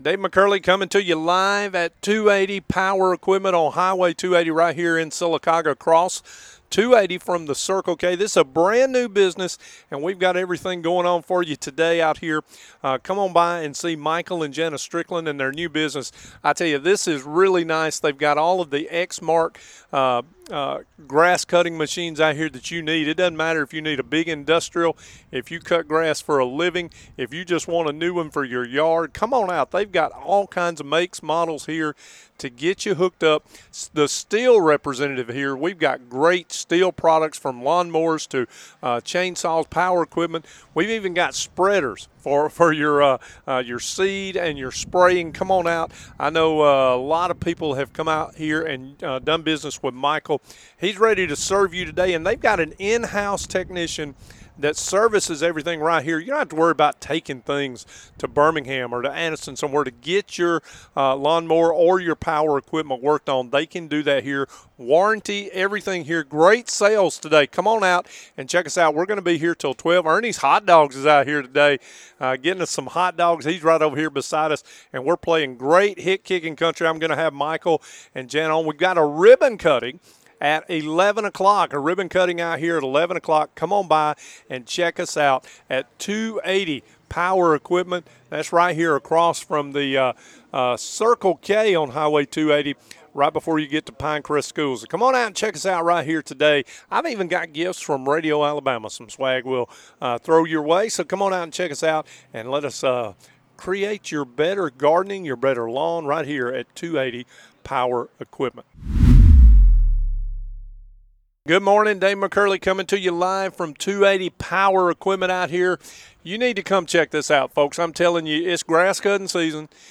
Live from 280 Power Equipment